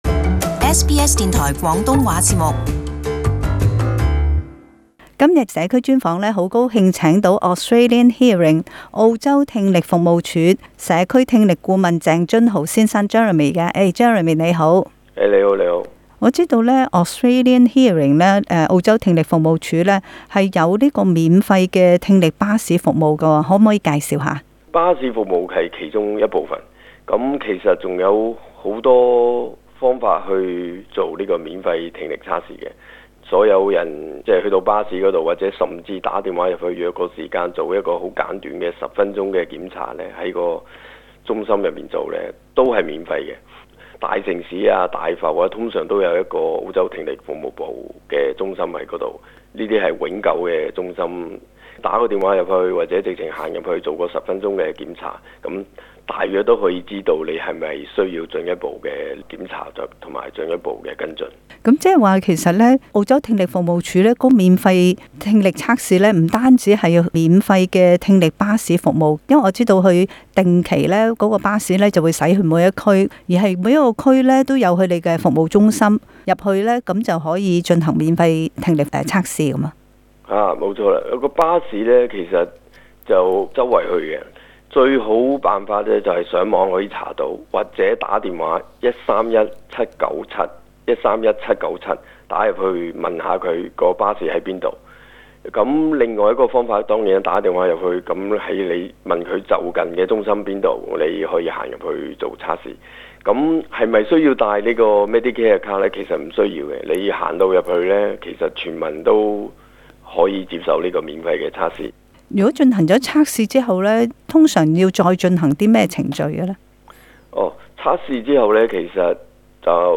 【社區專訪】免費聽力測試服務